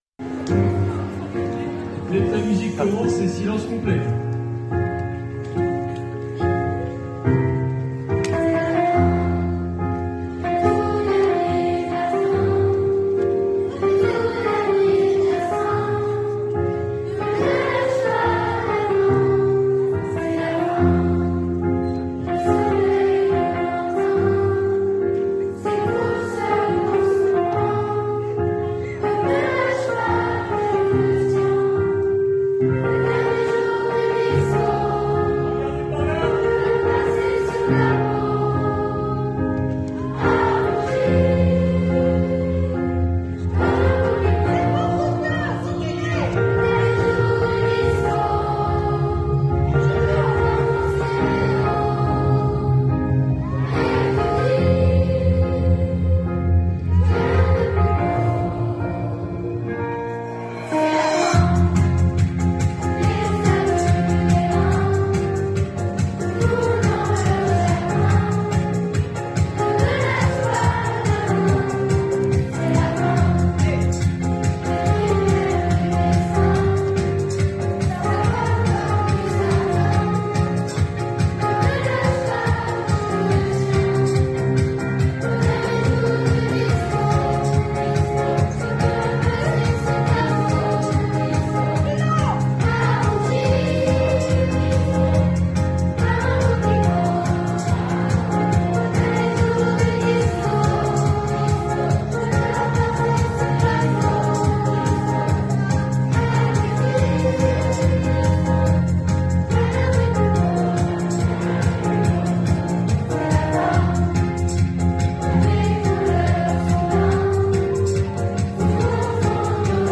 '' Les Voix de Queneau '' préparent le FESTIVAL des CHORALES 2024 & SCOOP !!!
Ce lundi 12 février était organisée une répétition pour la préparation du concert du Festival des Chorales...